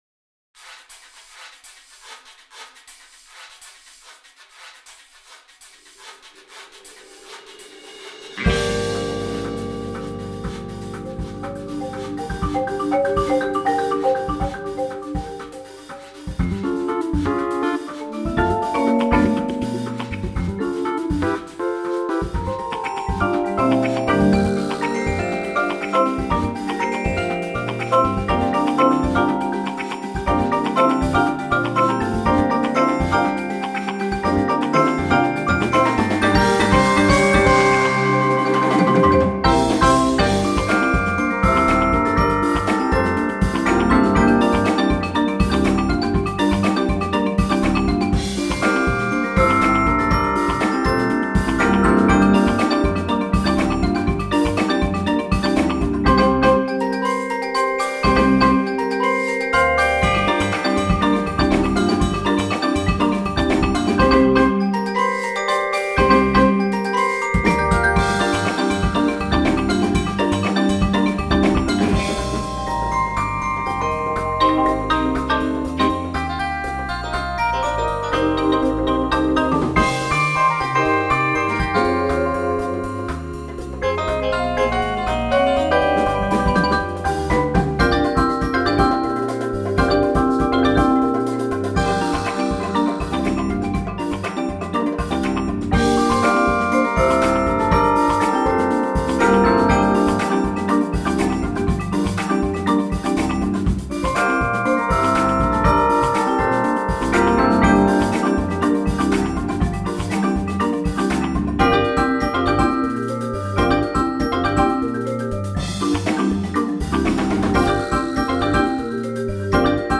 groovy, retro sounds of classic American TV cop shows
• Personnel: 16 players